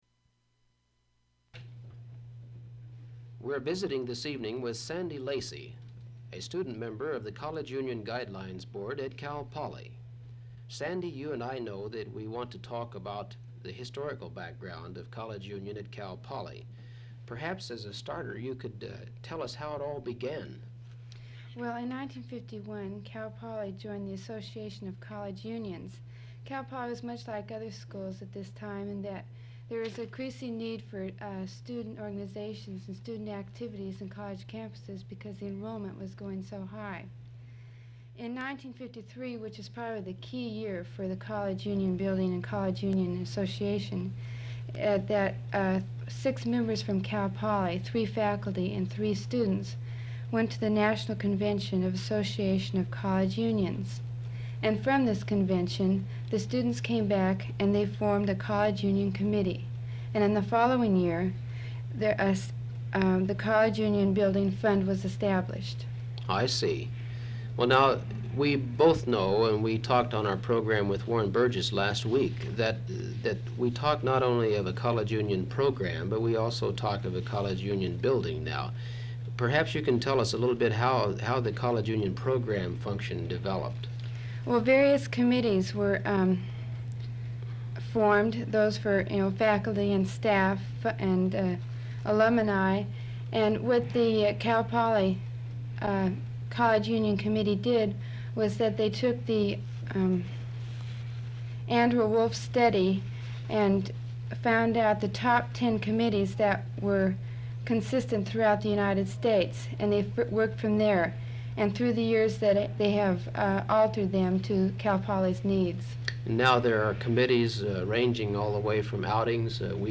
• Open reel audiotape